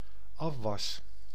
Ääntäminen
Synonyymit vaat Ääntäminen : IPA: [af.ʋas] Tuntematon aksentti: IPA: /ˈɑf.ʋɑs/ Haettu sana löytyi näillä lähdekielillä: hollanti Käännös Ääninäyte Substantiivit 1. dishes US Suku: m .